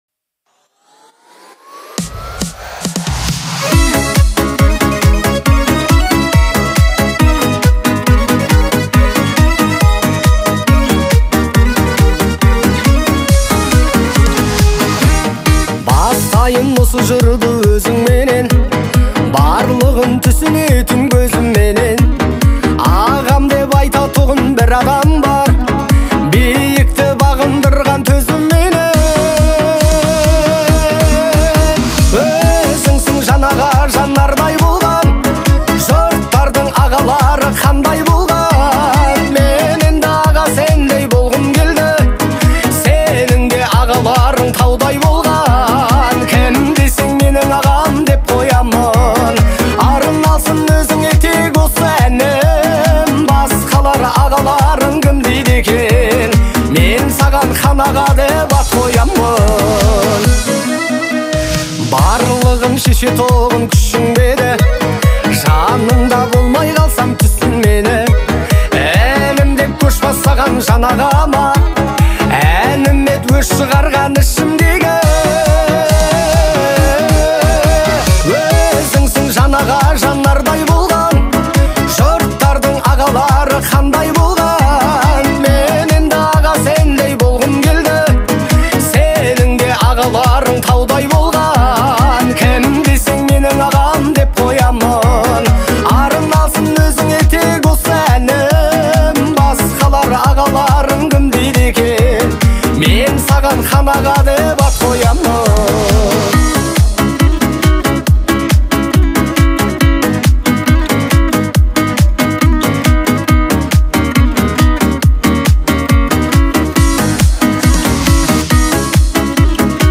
Категория: Казахские песни